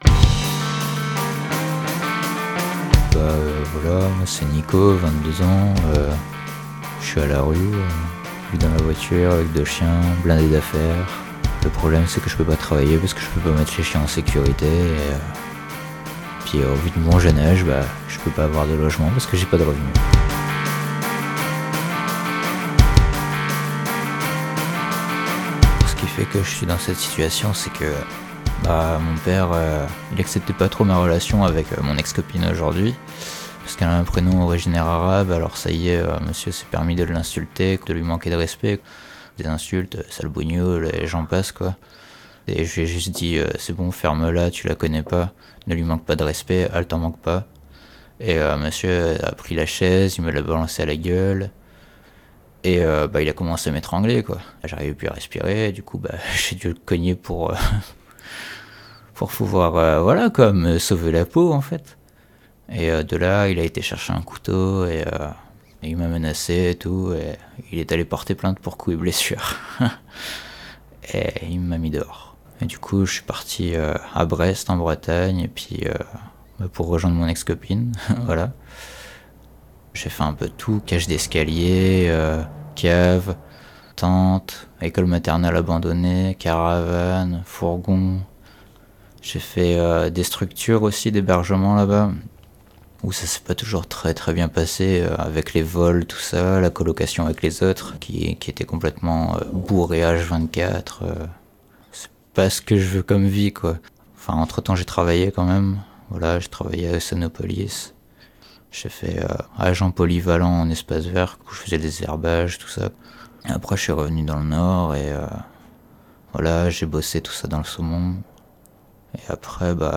Micros rebelles reportage